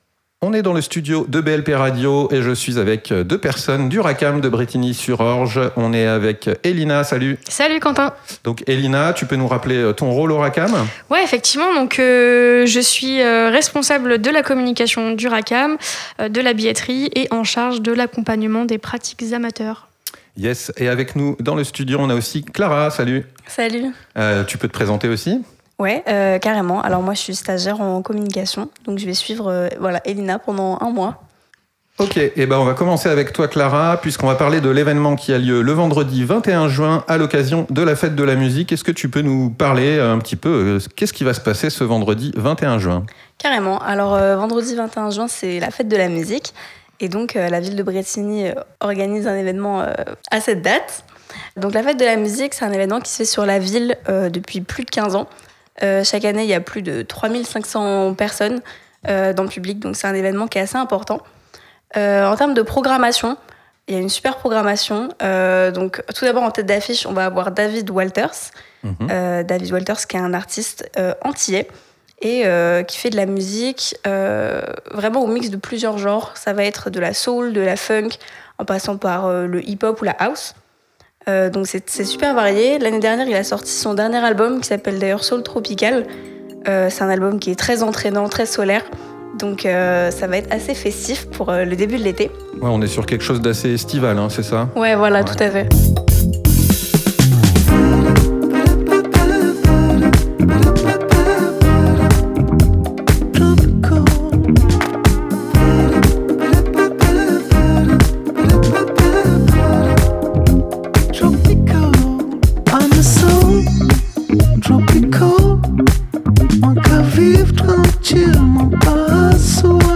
Reportages et interviews